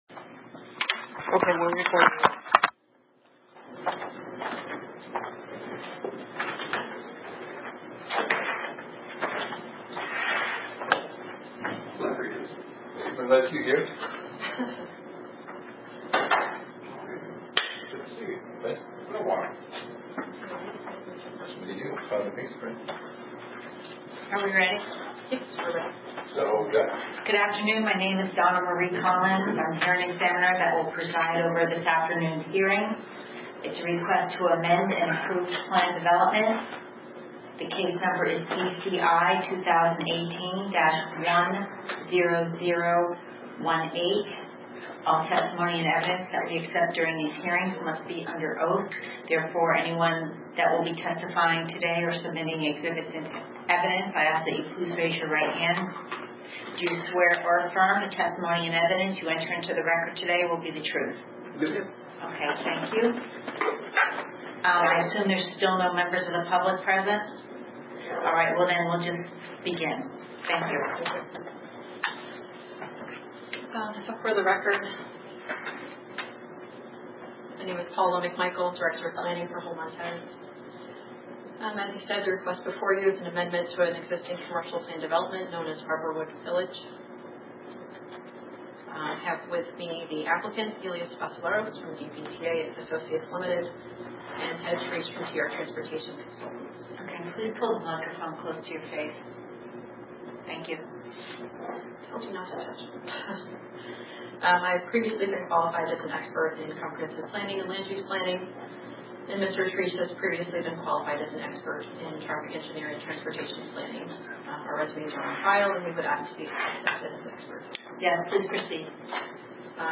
RECORDING OF THE AUGUST 8, 2019 HEARING FOR ARBORWOOD VILLAGE CPD